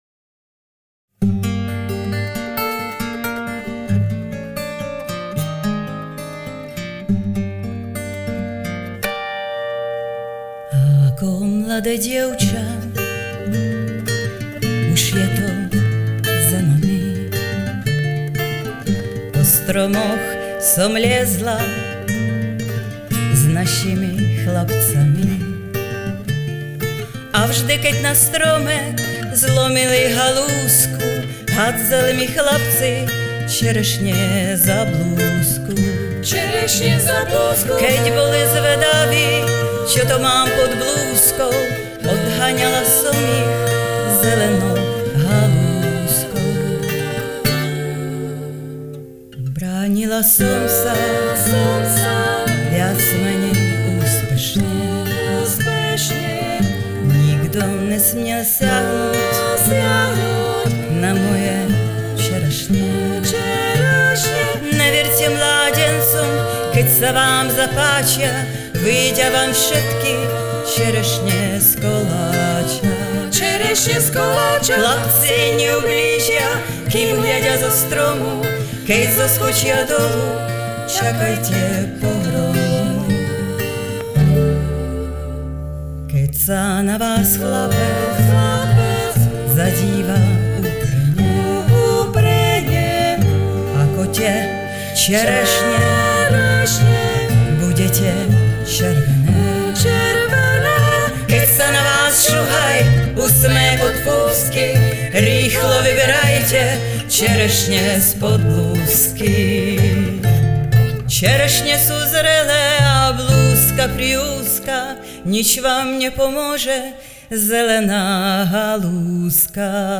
Kapela patří k předním vokálním skupinám v ČR.